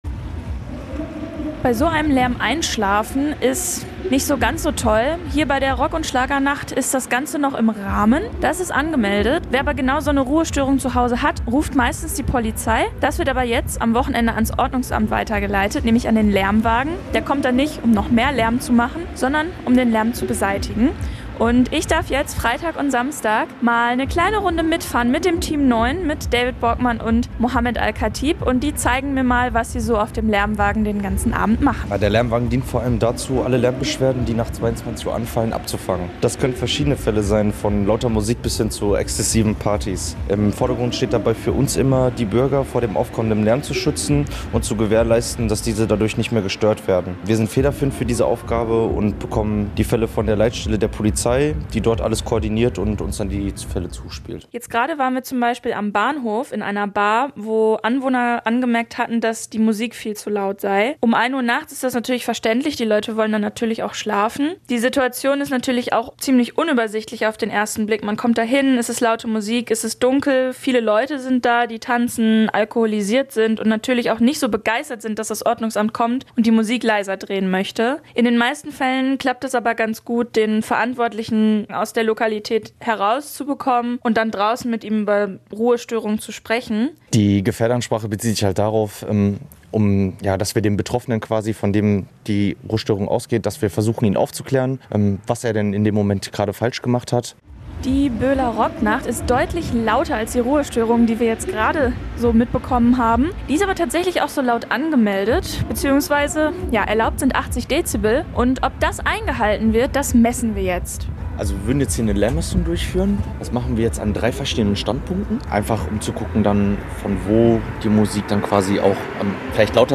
reportage-laermwagen.mp3